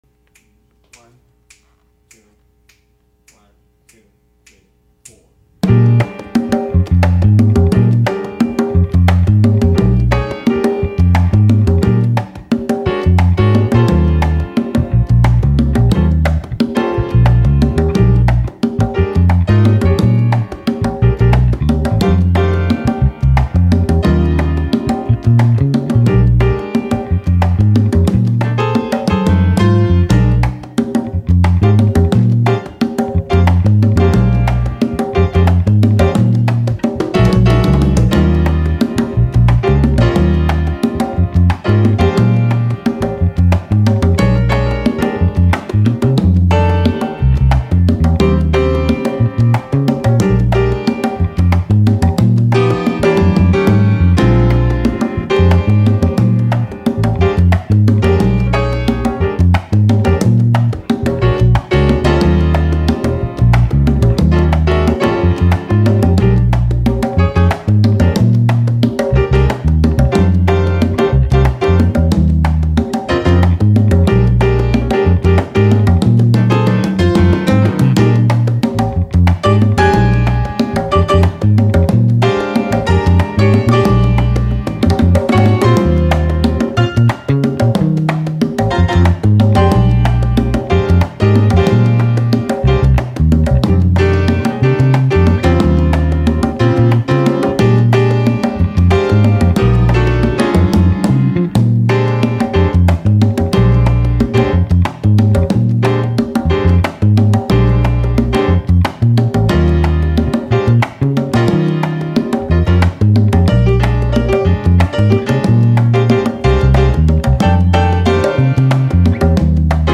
Bass, Piano, & Congas: Afro-Cuban 6/8
6_8_afro_cuban.mp3